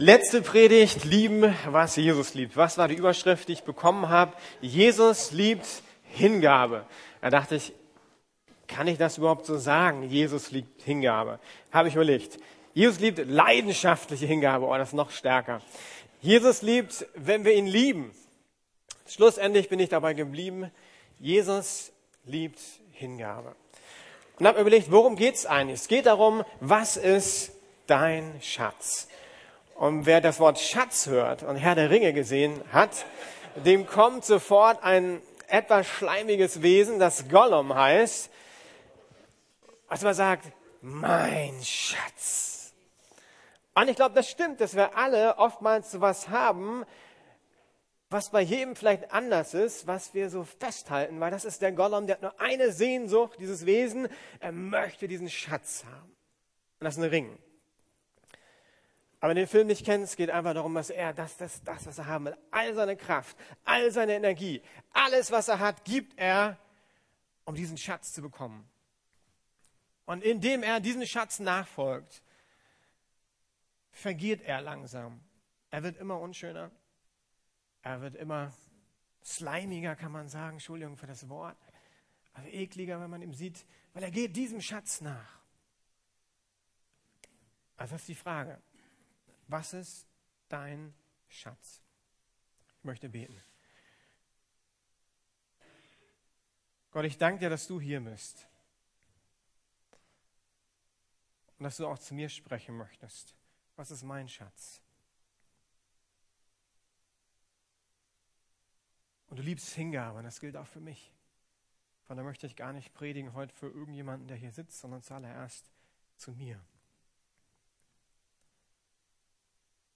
Lieben, was Jesus liebt - HINGABE ~ Predigten der LUKAS GEMEINDE Podcast